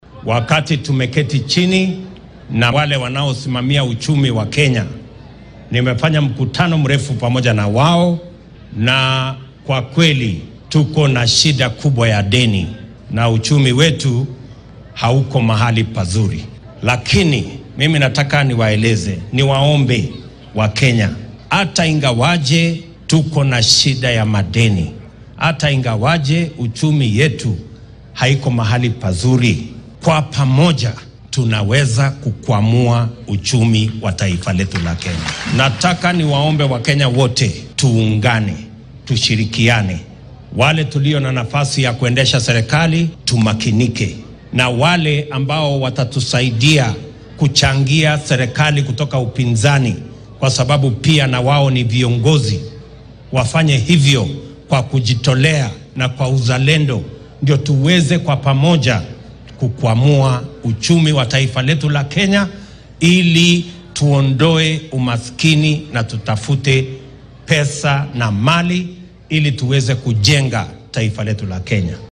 William Ruto ayaa arrimahan ka hadlay xilli uu shalay ku sugnaa magaalada Maua ee ismaamulka Meru.